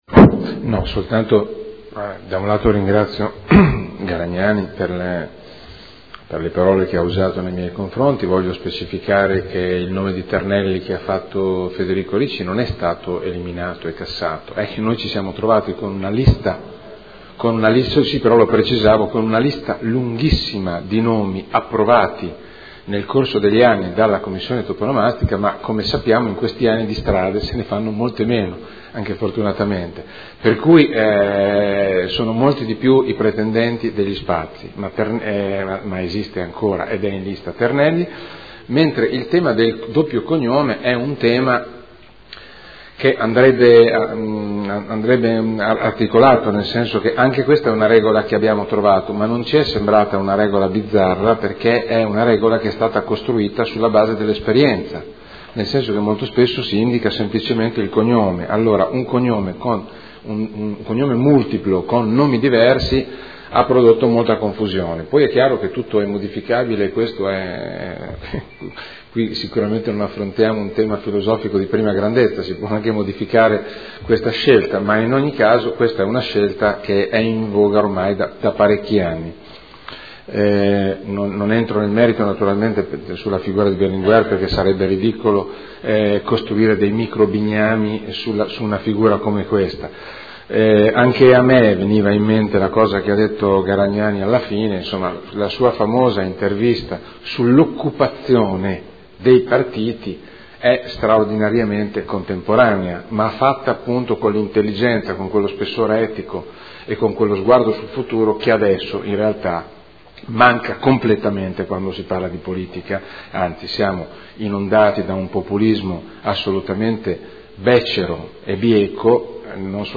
Roberto Alperoli — Sito Audio Consiglio Comunale